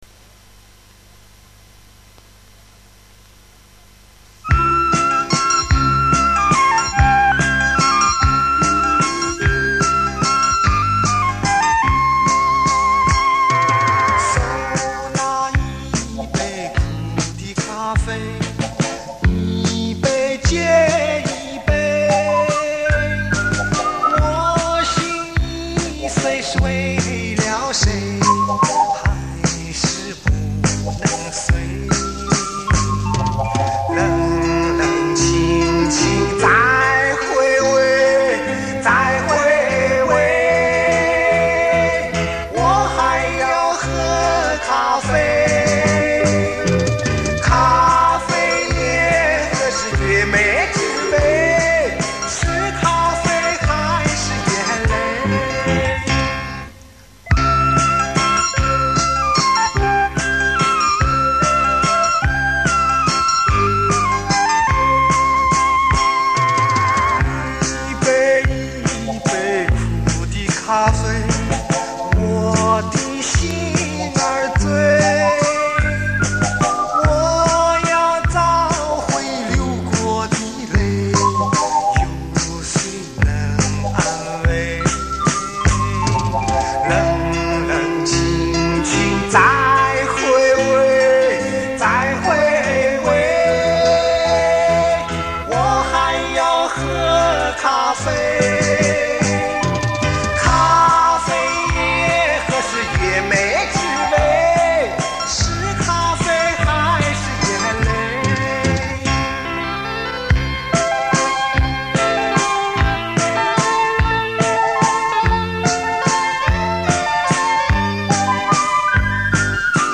（TDK磁带翻录）